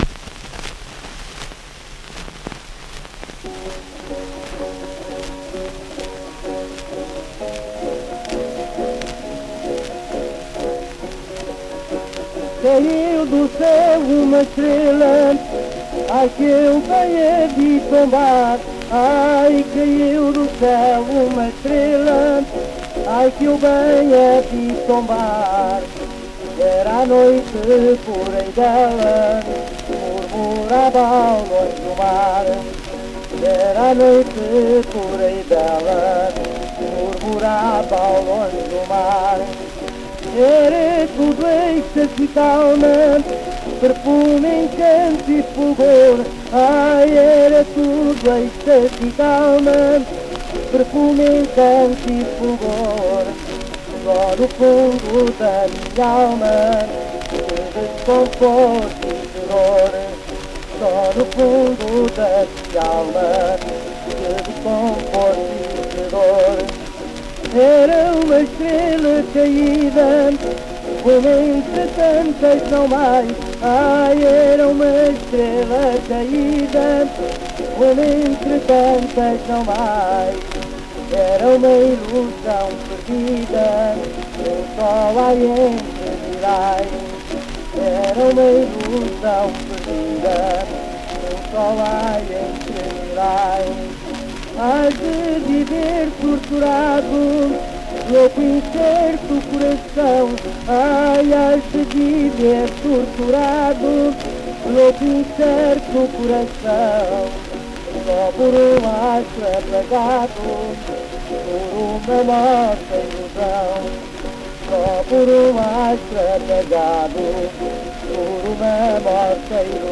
inetmd-fcsh-ifpxx-mntd-audio-fado_corrido-899.mp3